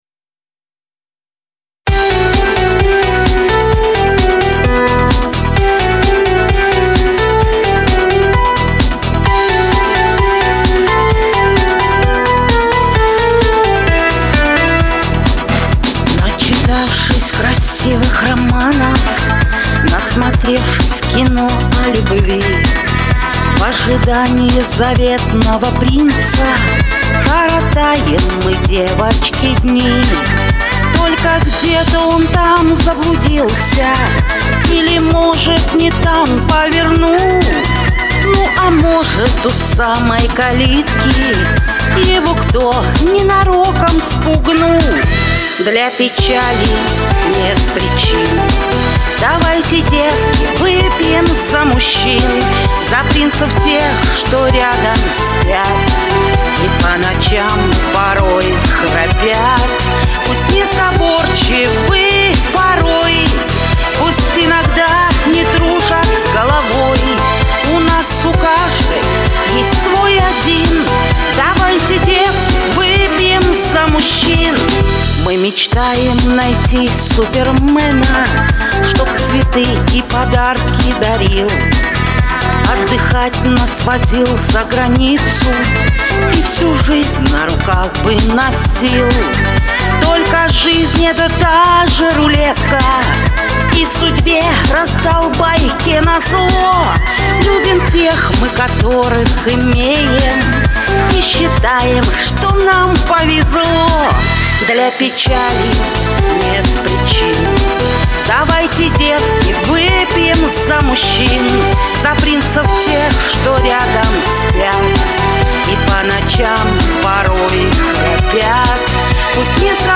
Категория: Шансон 2016